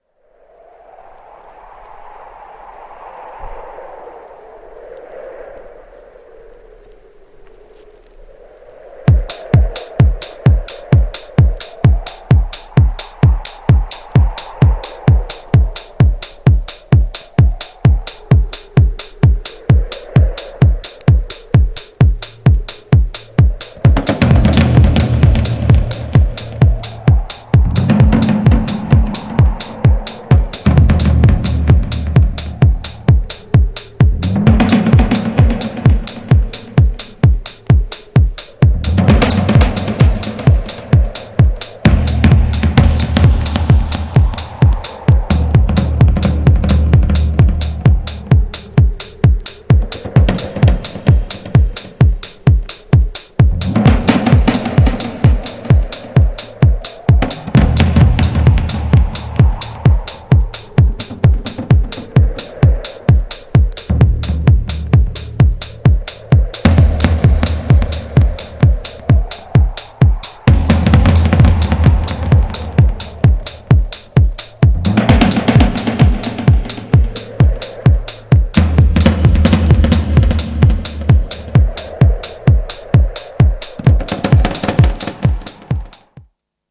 GENRE House
BPM 131〜135BPM